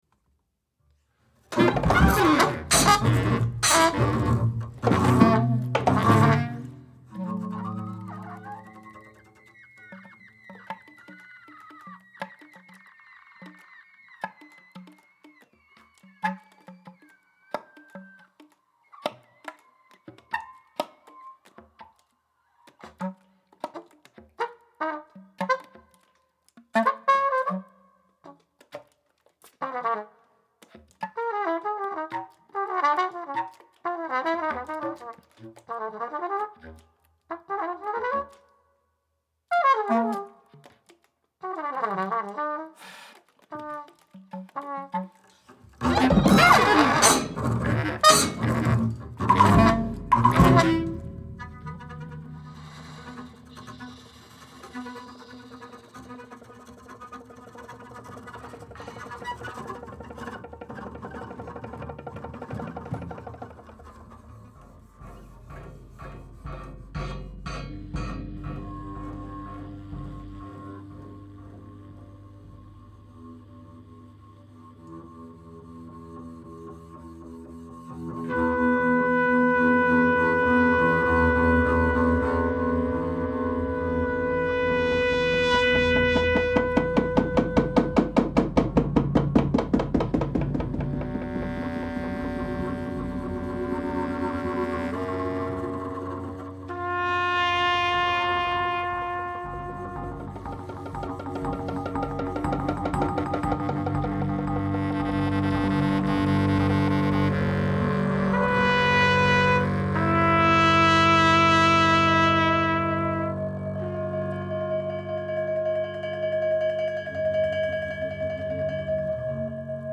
bass clarinet
trumpet
drums